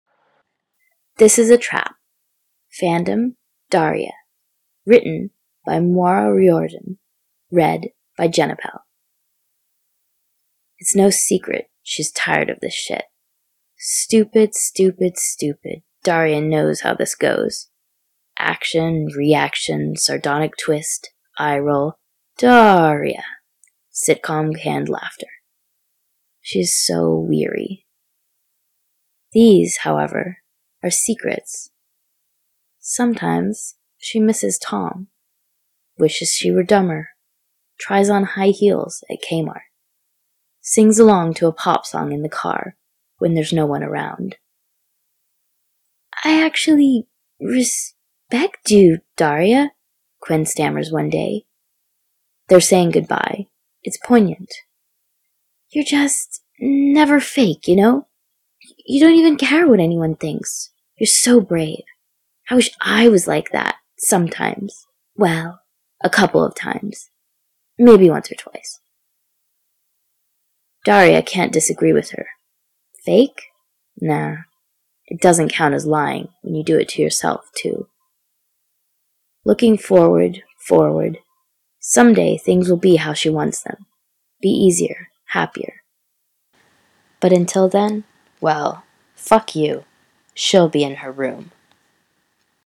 Podfic: 7 shorts for the "Awesome Ladies Ficathon"